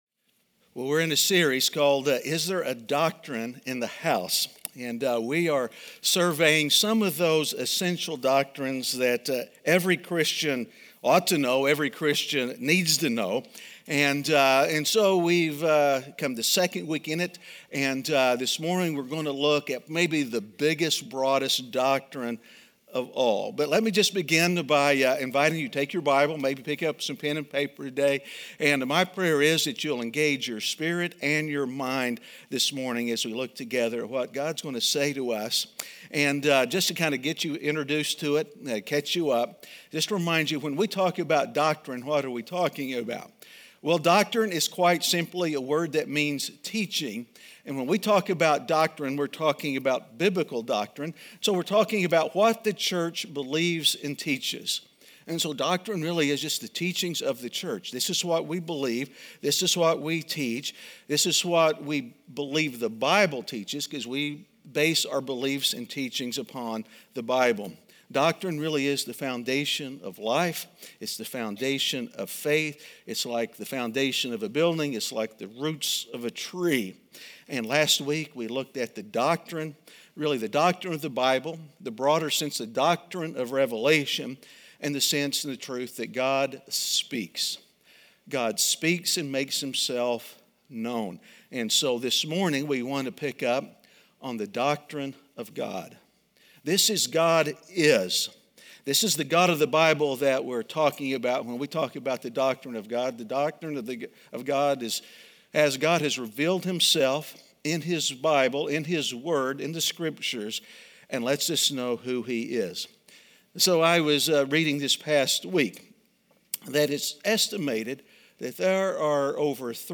A message from the series "Counting It All Joy."